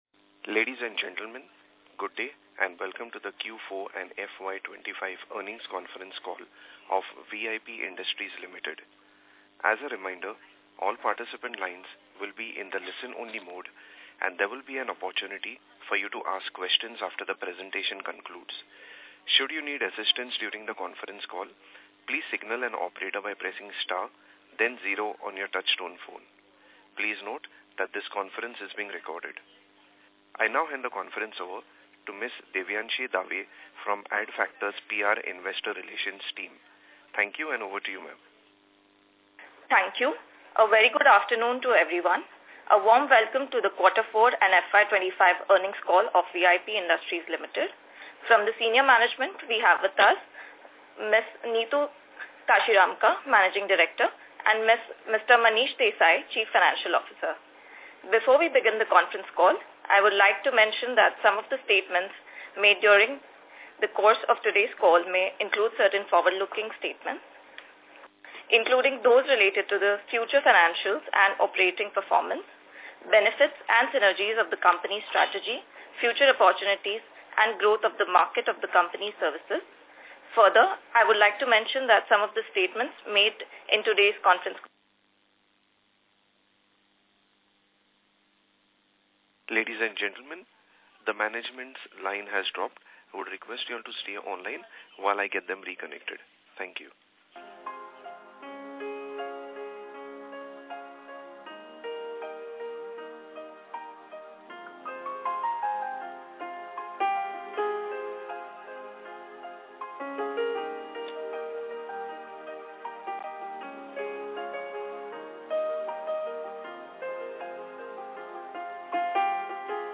Recording Of Concall | VIP Industries | Asia's Largest Luggage Manufacturer